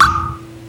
soft-hitwhistle.wav